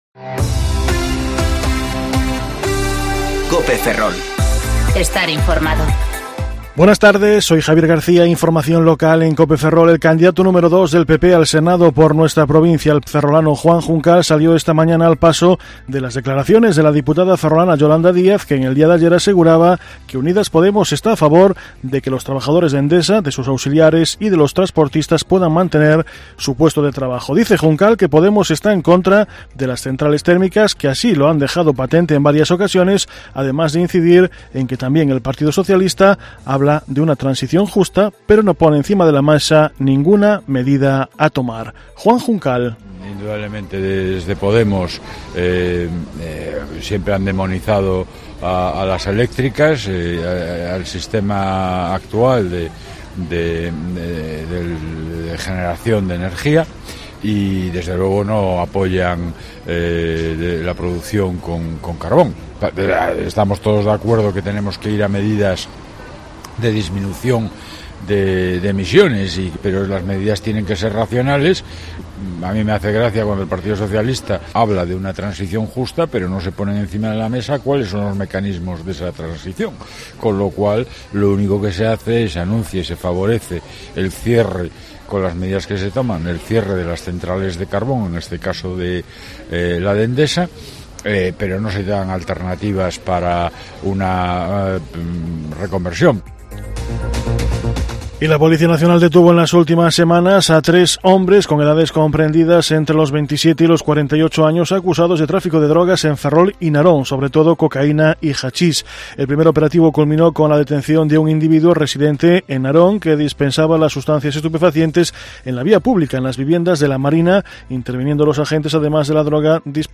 Informativo Mediodía Cope Ferrol 9/10/2019 (De 14.20 a 14.30 horas)